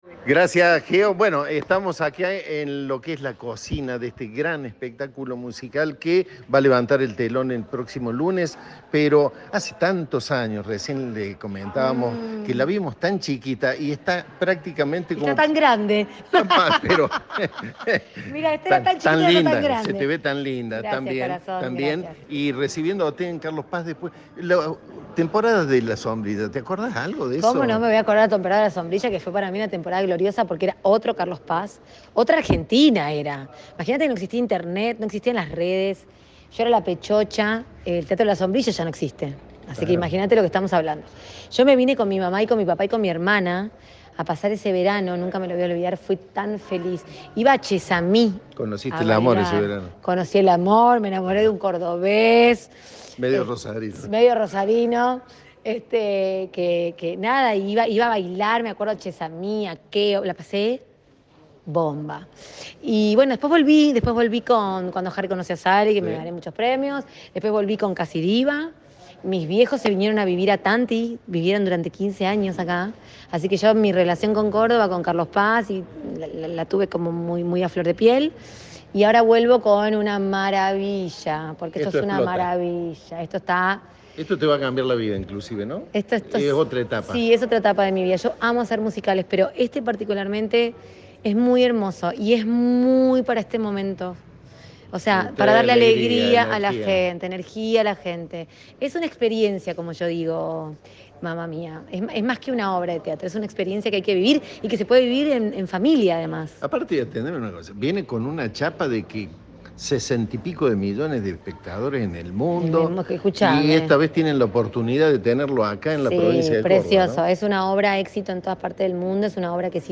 La comediante, en diálogo con Cadena 3, hizo un repaso de sus inicios en la villa serrana y habló de lo que será el espectáculo "Mamma Mía".